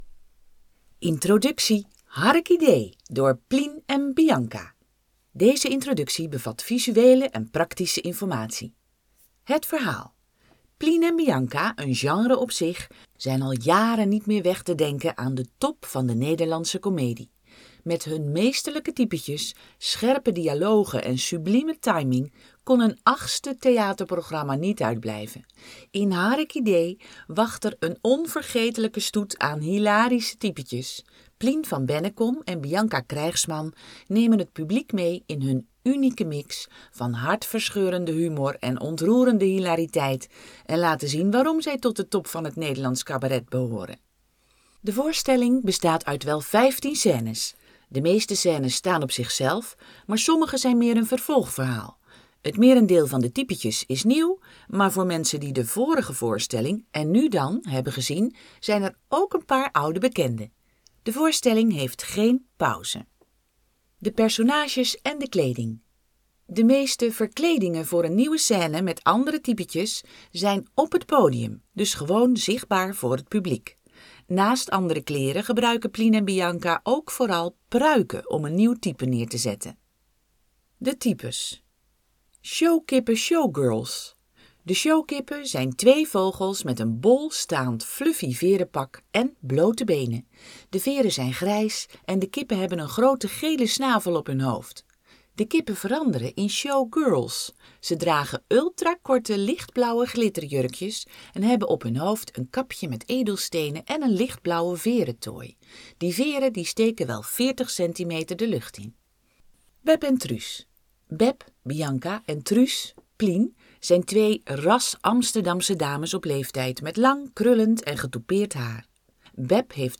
De introductie is hieronder beschikbaar als Word bestand, PDF, ingesproken audio en als website tekst.